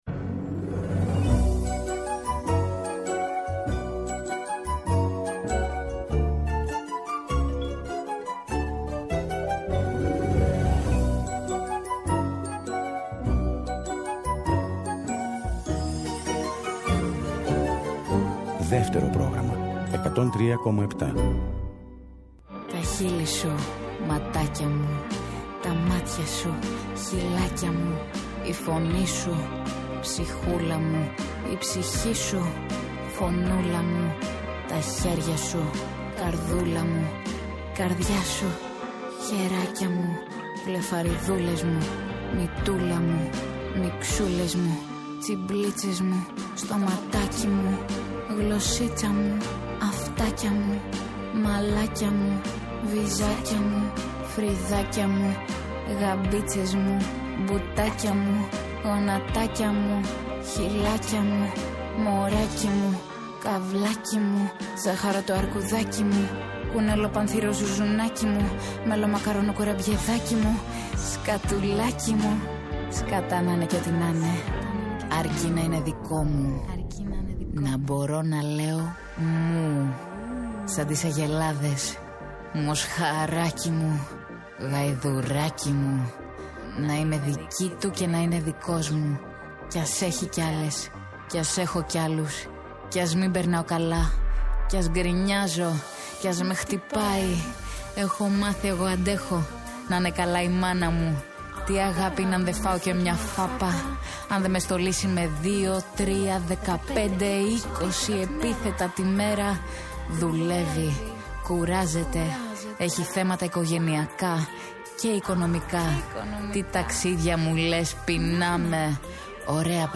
καλεσμένη τηλεφωνικά
Συνεντεύξεις